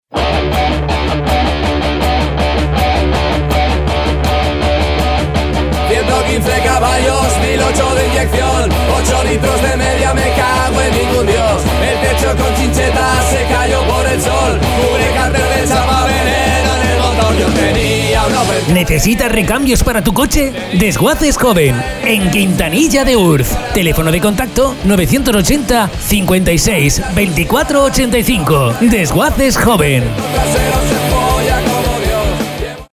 ESTA ES LA VOZ DEL LOCUTOR NACIONAL QUE GRABARA TU ANUNCIO